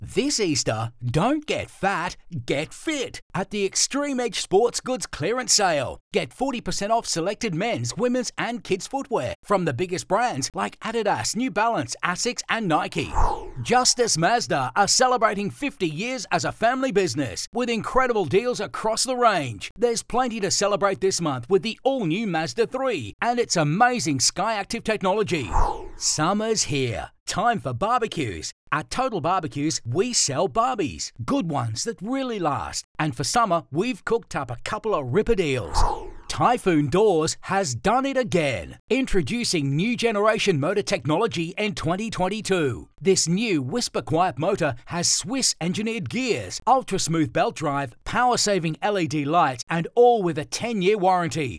• Retail Friendly
• Middle age male voiceovers
• Custom Voice Booth
• Microphone Neumann TLM 103